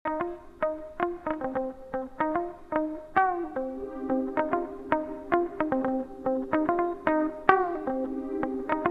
:great:Bei diesem Beispiel habe ich etwas Schwierigkeiten und hoffe, dass mir jemand hierbei helfen kann: C-bflat-F wird abgedämpft gespielt mit einem Bending bei F. Soweit konnte ich das raushören, dennoch...
Ich finde, dass bei dem Beispiel die Töne noch stärker hörbar sind und selbst das Bending leicht abgedämpft oder dumpf klingt.